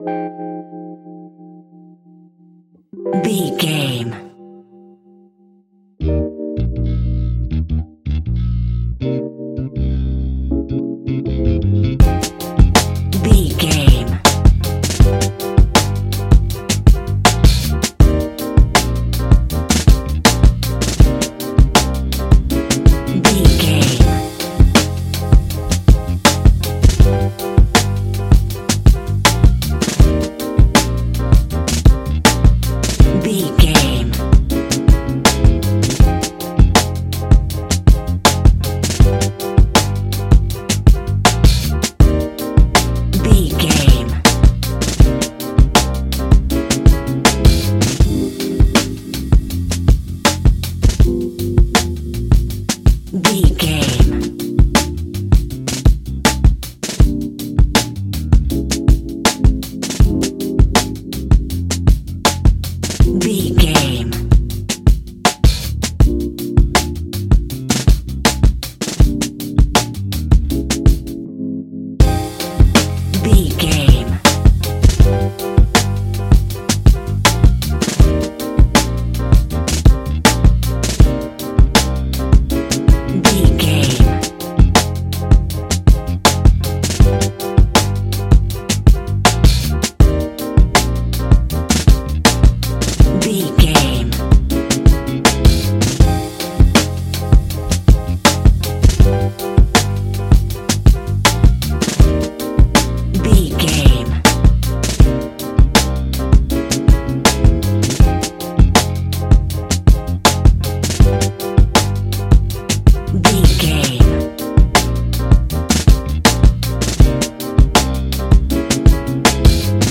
Ionian/Major
F♯
chilled
laid back
Lounge
sparse
new age
chilled electronica
ambient
atmospheric
morphing